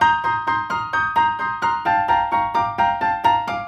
Index of /musicradar/gangster-sting-samples/130bpm Loops
GS_Piano_130-G1.wav